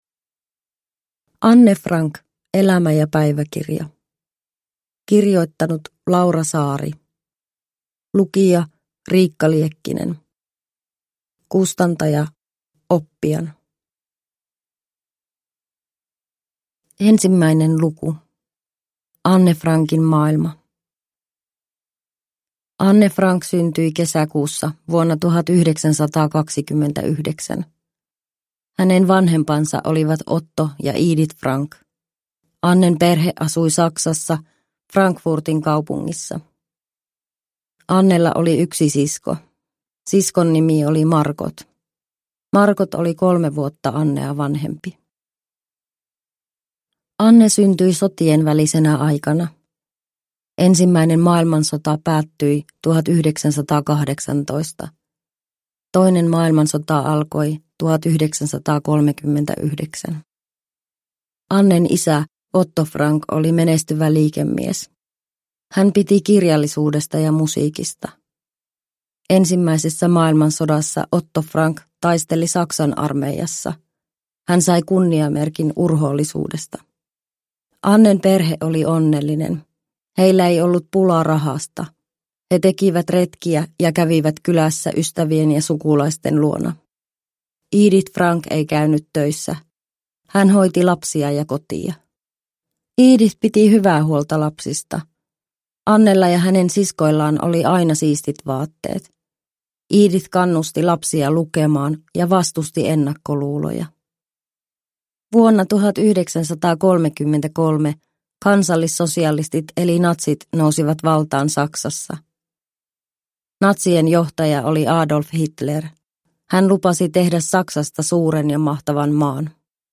Anne Frank (ladattava selkoäänikirja) – Ljudbok